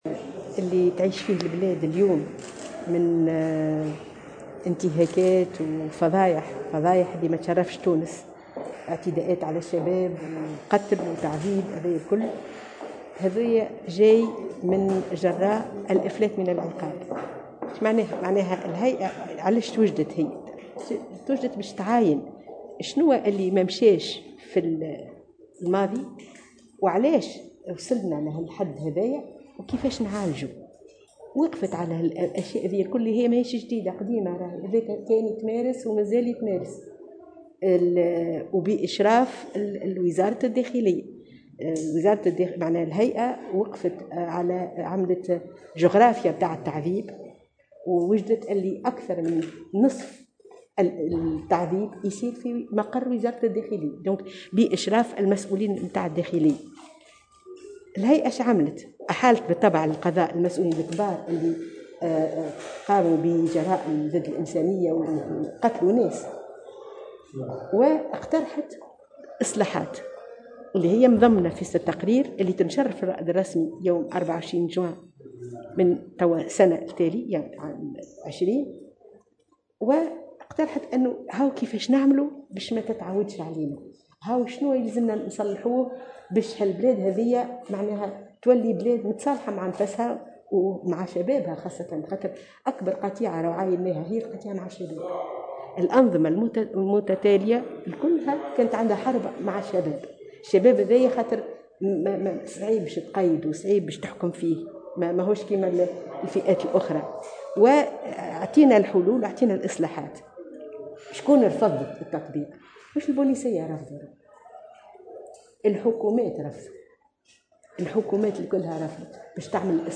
قالت الرئيسة السابقة لهيئة الحقيقة والكرامة، سهام بن سدرين، في تصريح لمراسلة الجوهرة أف أم، إن الانتهاكات التي تقوم بها القوات الأمنية في الآونة الأخيرة لا تشرف تونس، وهي نتيجة طبيعية لتواصل ظاهرة الإفلات من العقاب.
ولاحظت بن سدرين على هامش ندوة وطنية حول التحركات التصعيدية ضد الإفلات من العقاب، اليوم السبت، أن نصف الانتهاكات وحالات التعذيب في تونس تُمارس في المقرات الراجعة بالنظر لوزارة الداخلية، تحت إشراف مسؤولي الوزارة، وهو ما تواصل بعد الثورة، بسبب رفض الحكومات المتعاقبة لإجراء إصلاحات على الأسلاك الأمنية وتكريسها لظاهرة الافلات من العقاب.